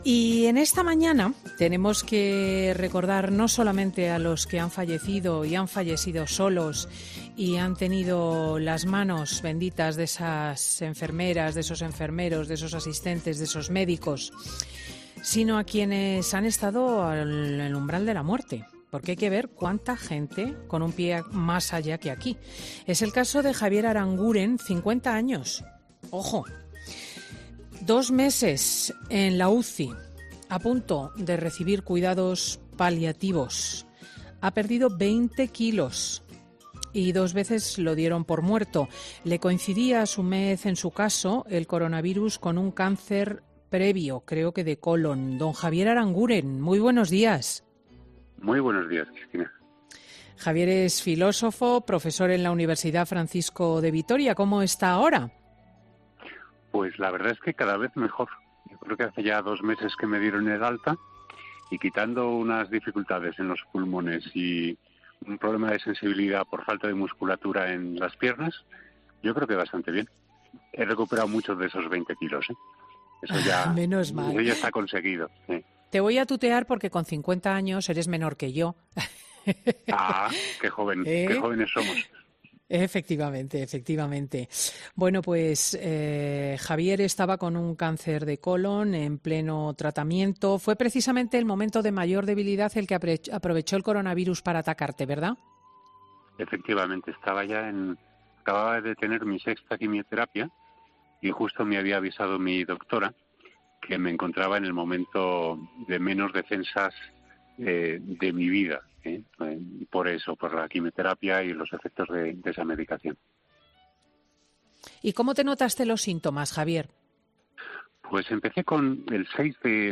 Escucha la entrevista completa con Cristina López Schlichting en 'Fin de Semana'.